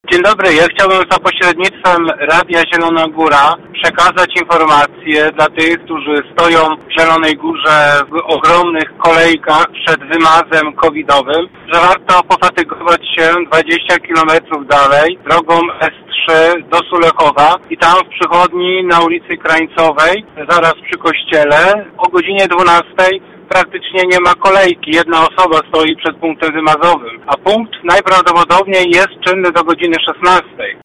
Z taką propozycją za pośrednictwem Poczty Głosowej zwrócił się do nas słuchacz: